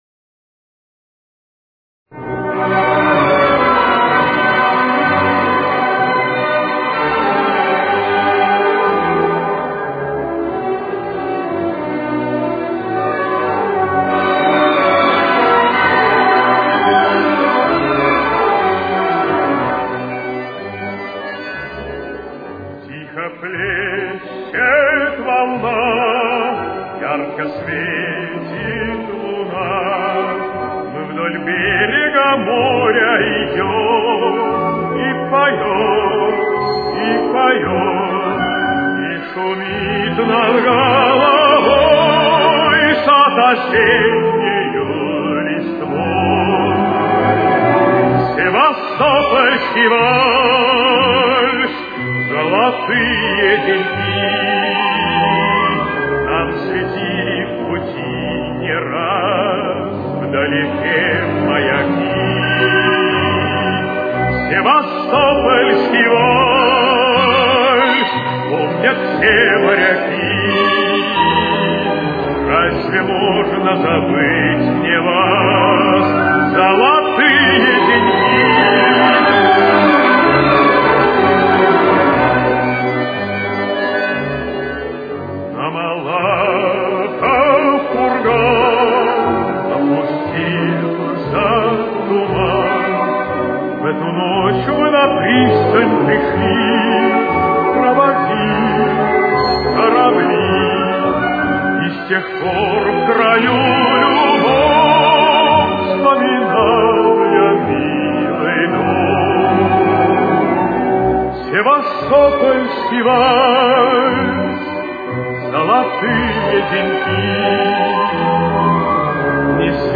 с очень низким качеством
Си минор. Темп: 182.